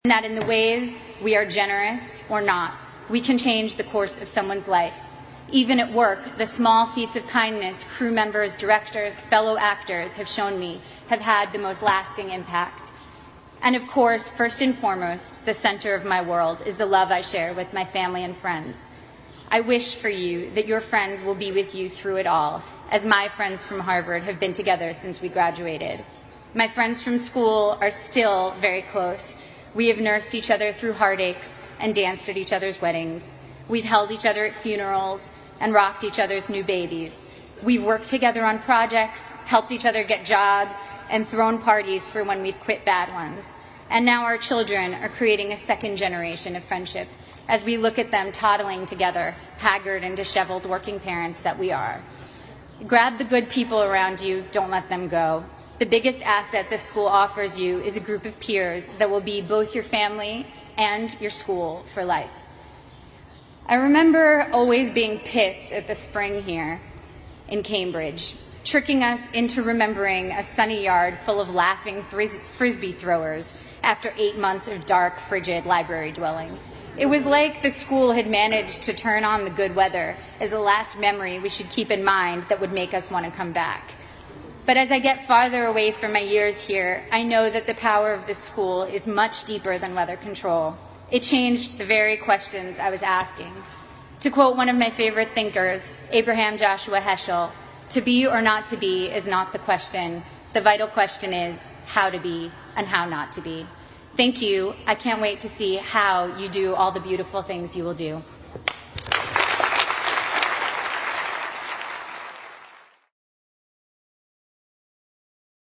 娜塔莉波特曼哈佛大学演讲 第10期 听力文件下载—在线英语听力室
在线英语听力室娜塔莉波特曼哈佛大学演讲 第10期的听力文件下载,哈佛牛津名人名校演讲包含中英字幕音频MP3文件，里面的英语演讲，发音地道，慷慨激昂，名人的效应就是激励他人努力取得成功。